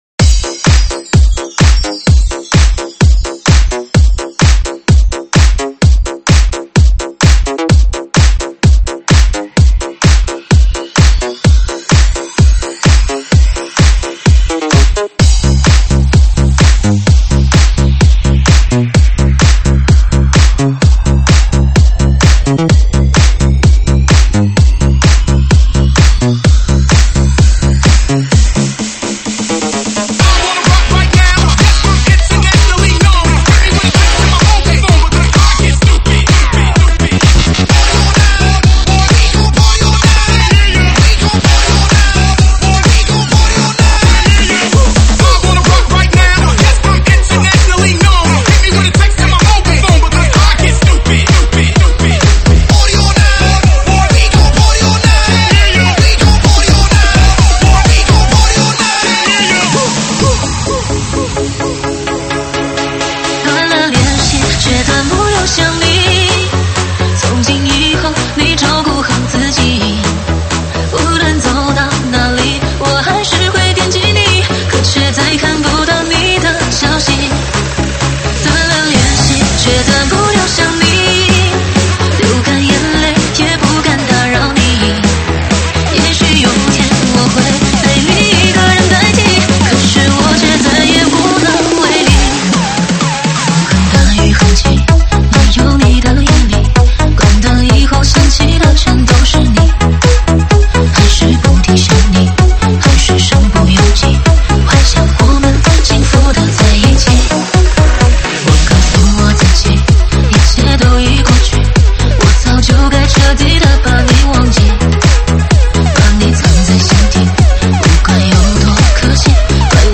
舞曲类别：中文舞曲